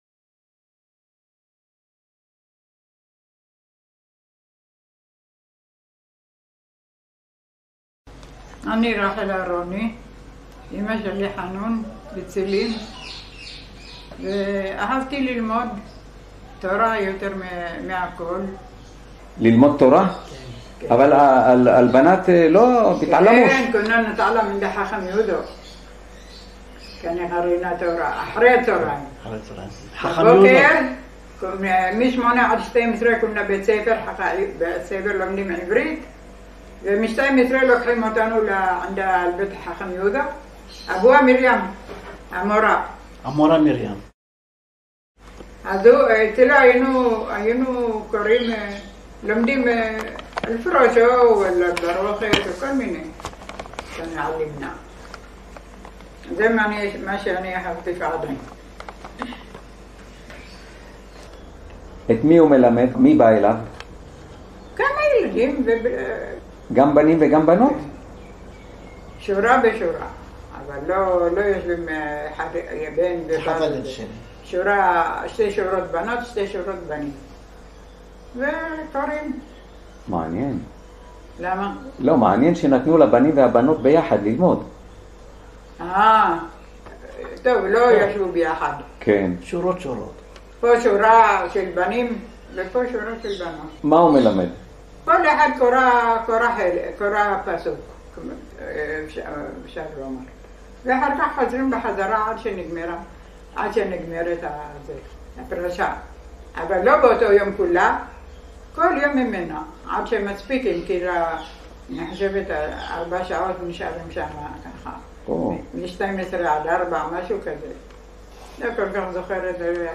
סיפורים ועדויות ממקור ראשון